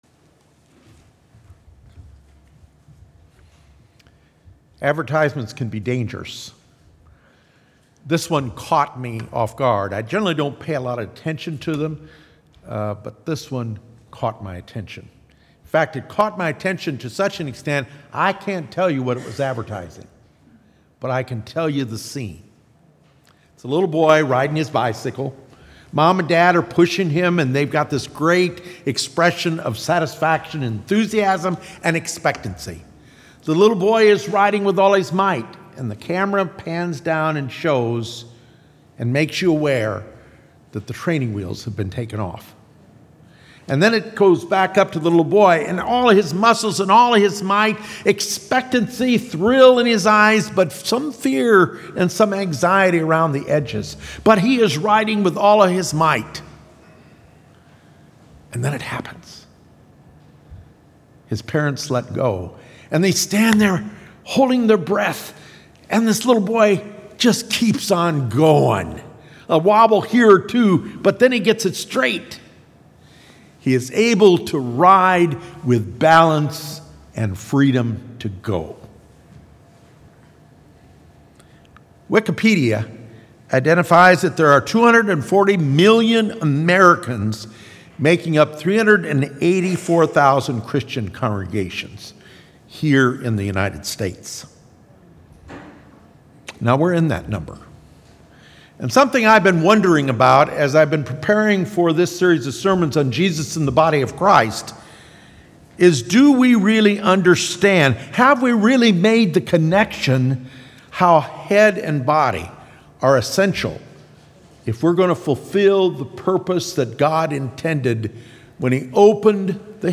Making the Connection | Oakton Church of the Brethren